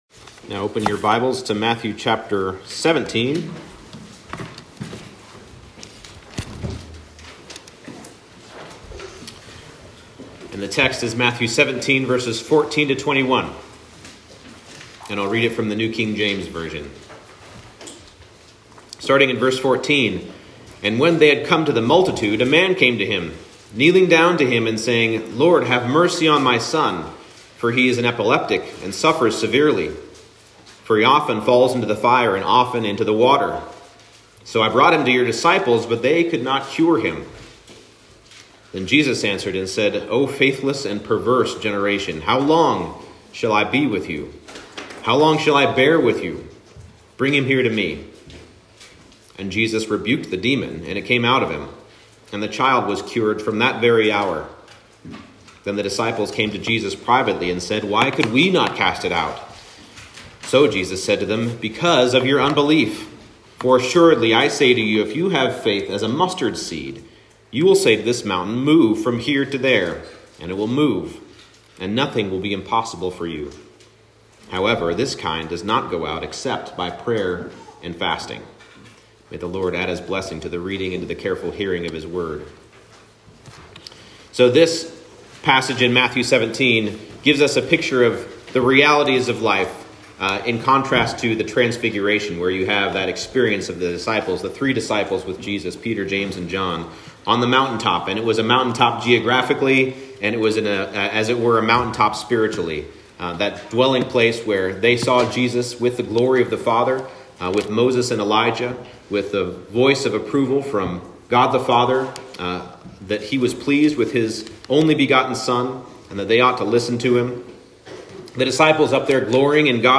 Matthew 17:14-21 Service Type: Morning Service Faith that overcomes obstacles is faith that persists in prayer.